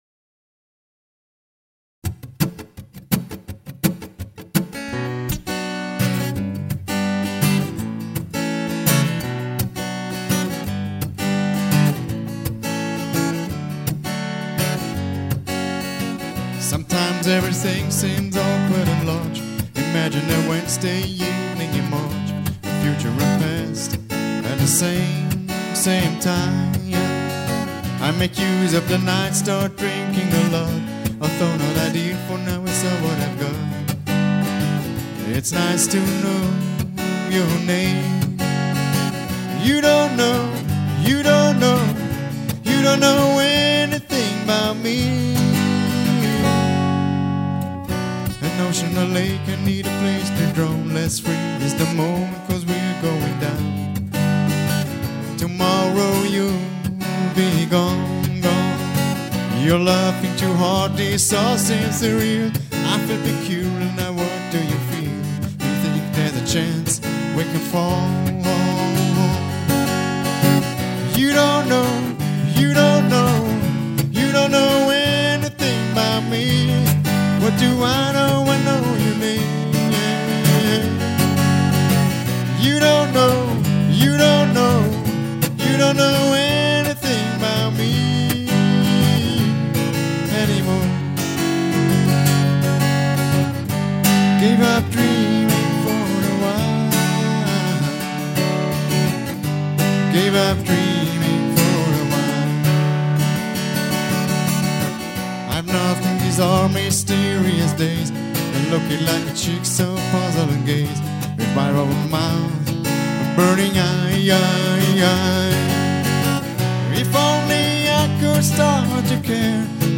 MIT VIEL SOUL BLUESIG GESUNGEN UND EINEM HAUCH VON
COUNTRY, GOSPEL, JAZZ, ROCK'N'ROLL & REGGAE UMWEHT.
Begleitet mit akustischer Gitarre.